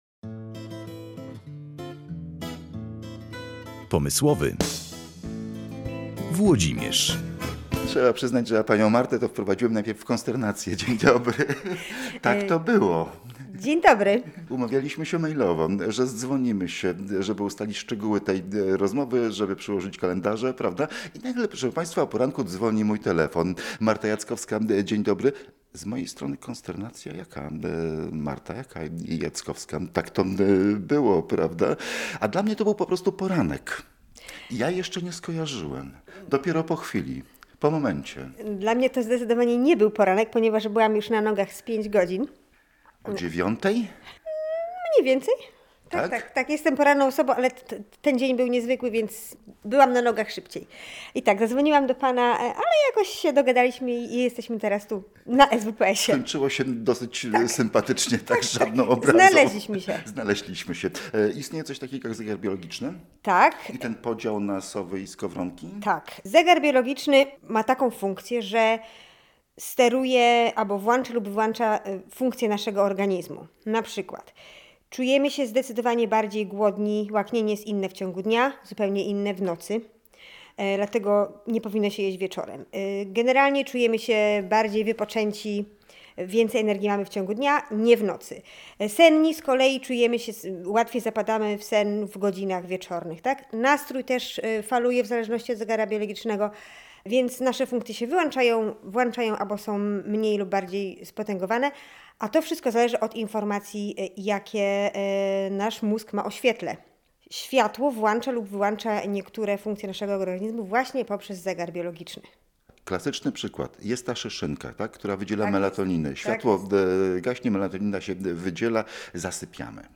Spanie się opłaca – rozmowa z psycholożką zdrowia